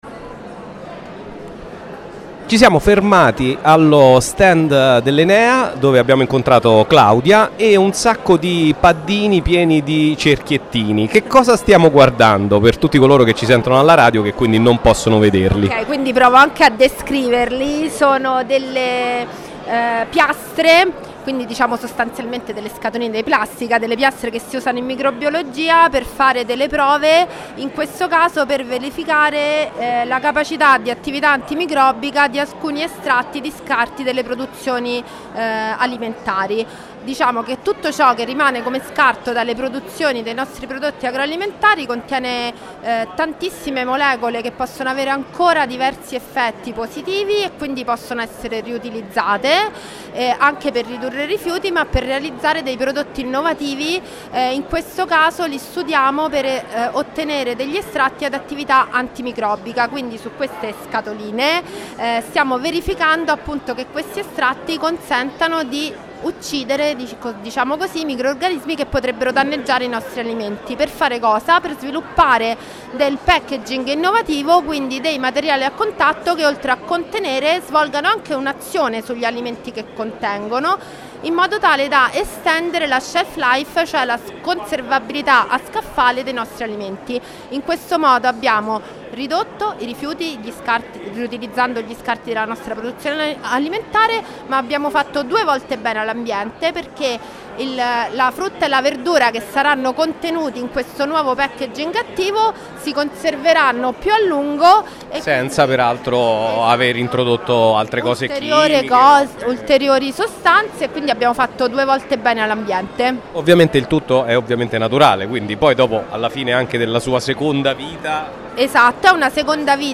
ENEA. Maker Faire 2025 | Radio Città Aperta
MakerFaire2025_Enea.MP3